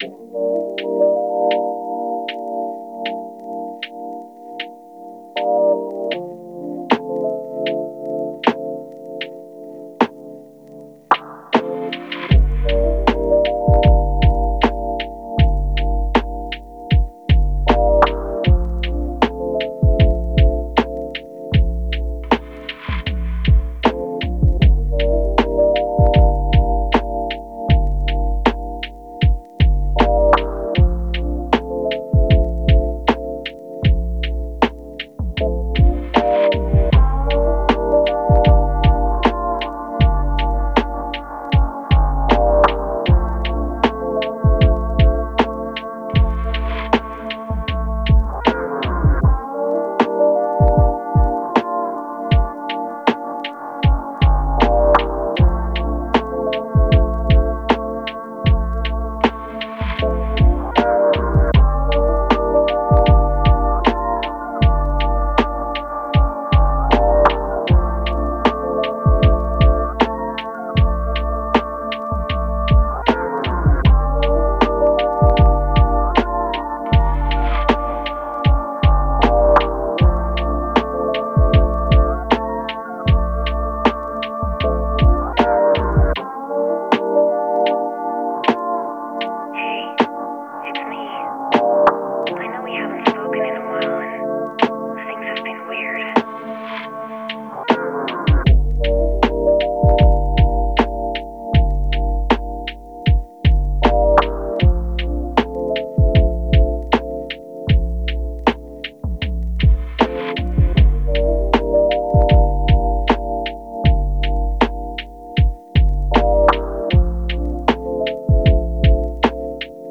BPM78 Key Dm
BPM78 keyDm 構成 イントロ4 バース16 フック8 間奏4 バース16 フック16 アウトロ4